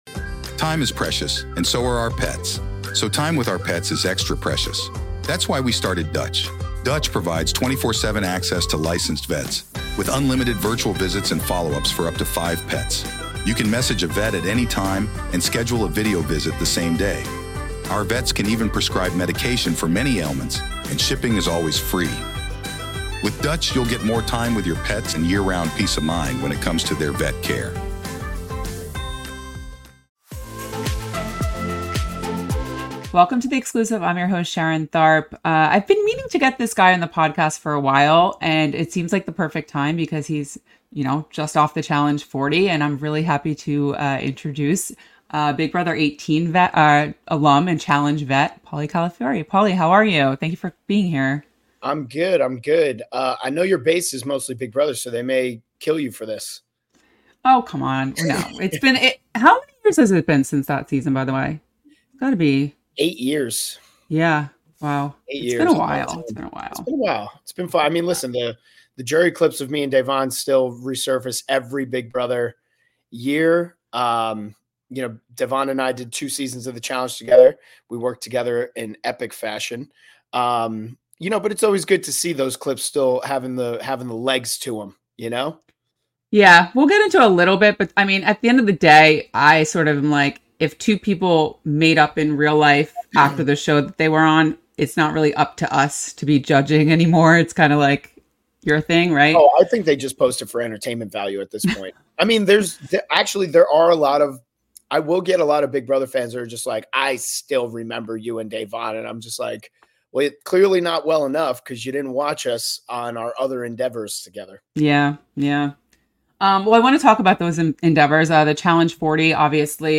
Exit Interview